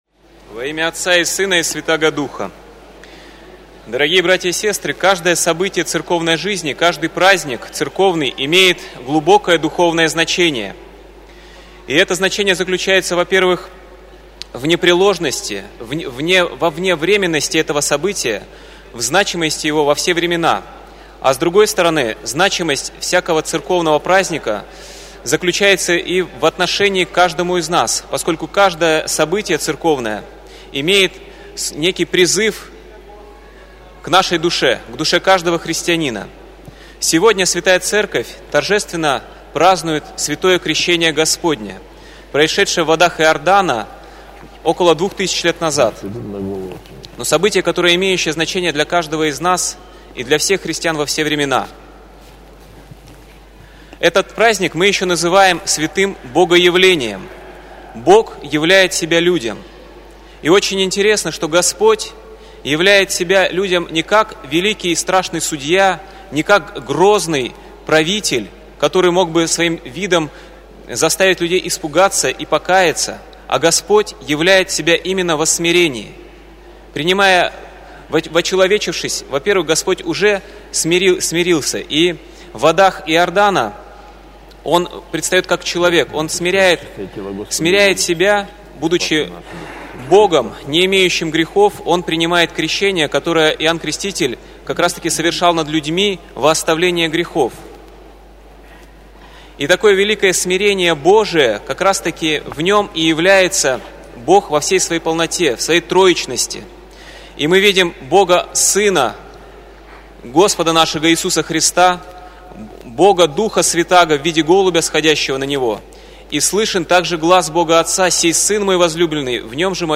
Проповедь на Литургии